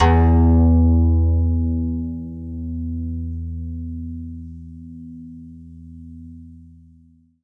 52-str01-zeng-e1.aif